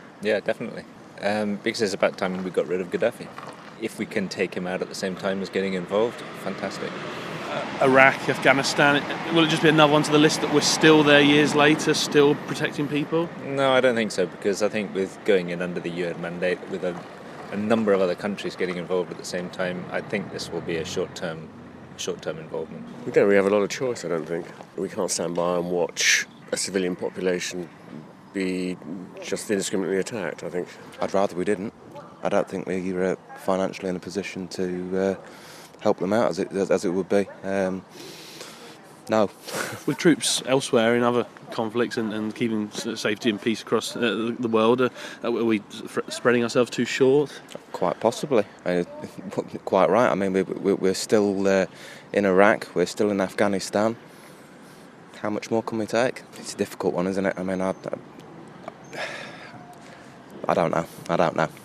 asked the people in Leighton Buzzard if they agree with the action in Libya...